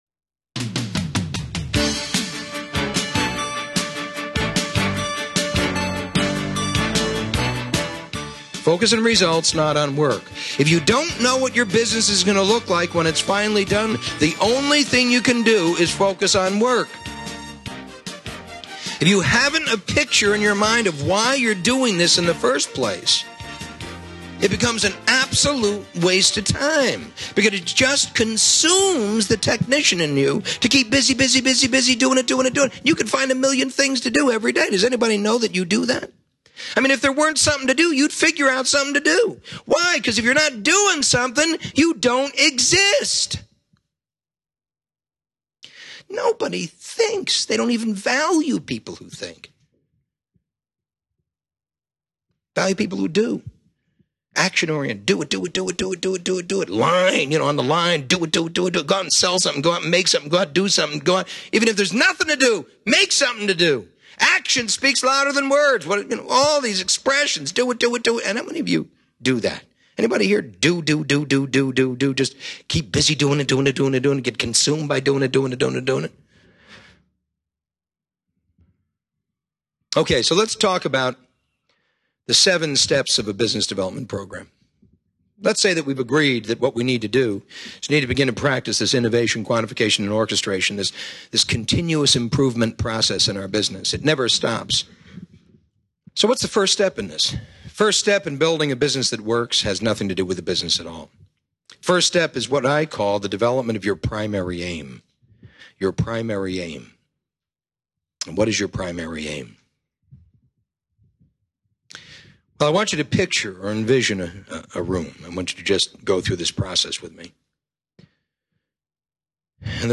This audio is from the EMyth Seminar Nightingale Conant tape set.
EMyth Seminar Tape 2 of 6